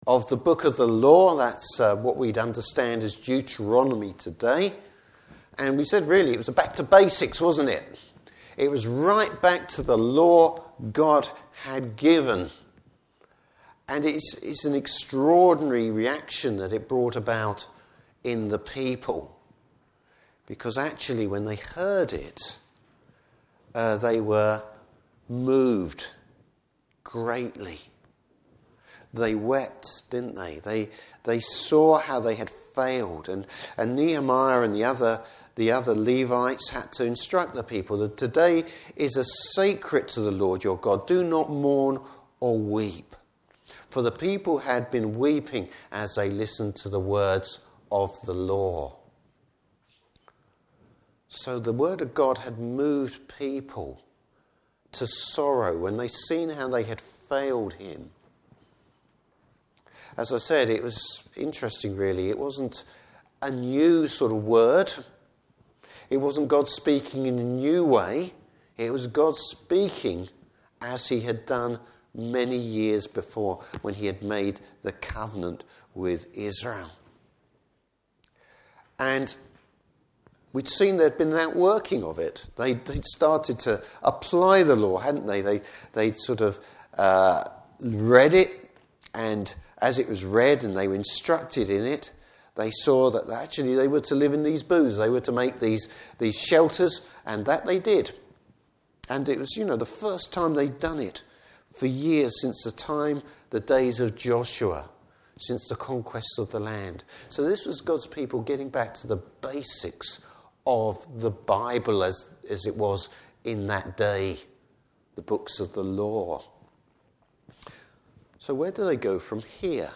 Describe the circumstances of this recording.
Service Type: Morning Service Bible Text: Nehemiah 9:1-37.